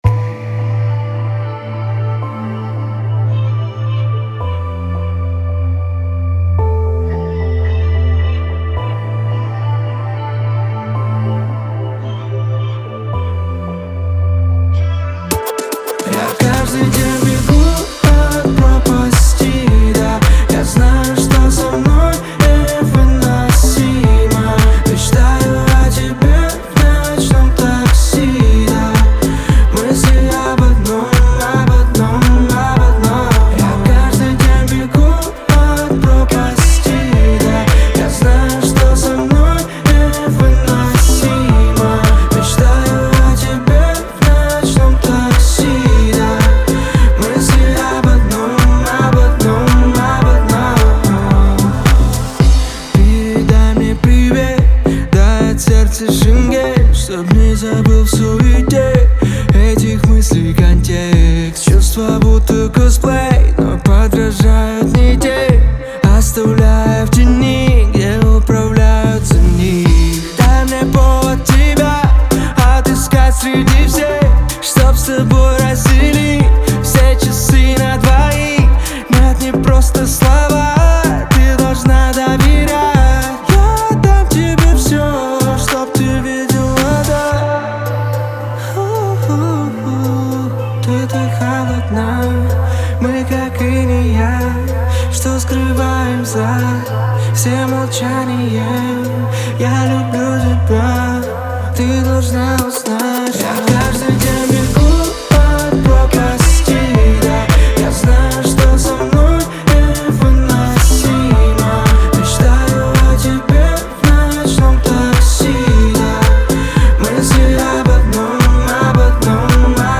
эмоциональный трек в жанре поп-рок
передает свои эмоции через искренний вокал